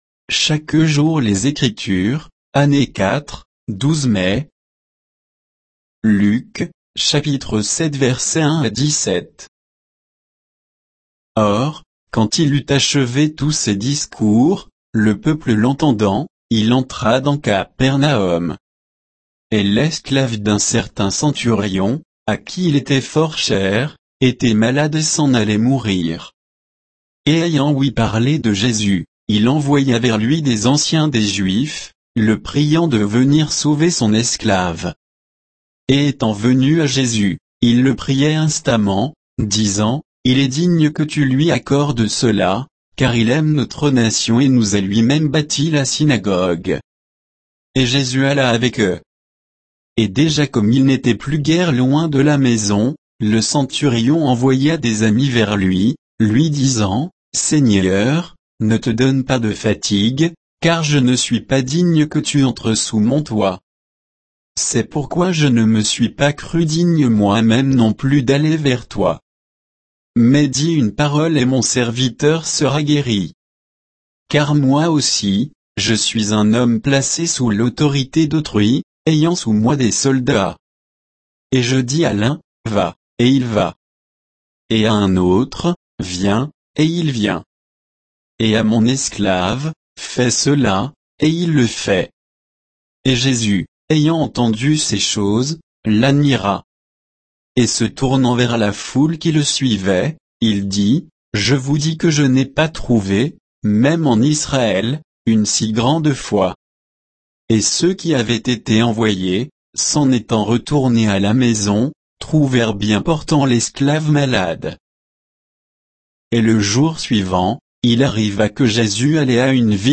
Méditation quoditienne de Chaque jour les Écritures sur Luc 7